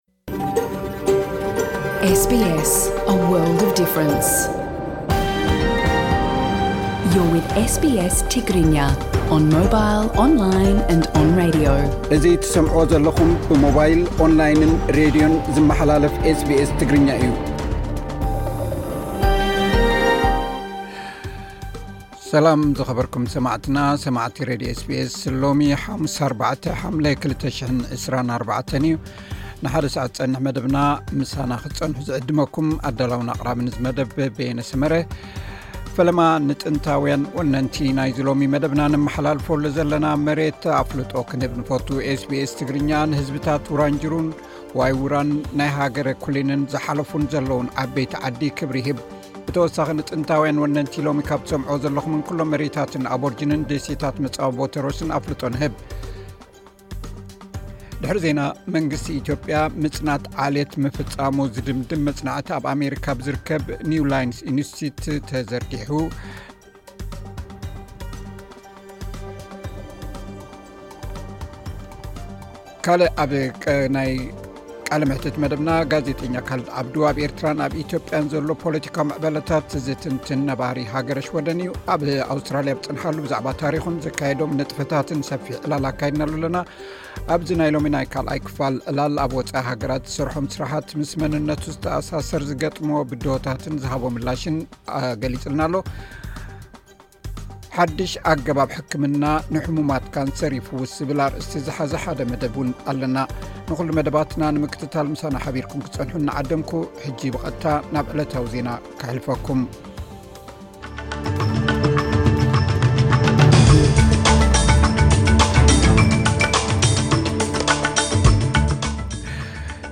ዕለታዊ ዜና ኤስ ቢ ኤስ ትግርኛ (04 ሓምለ 2024)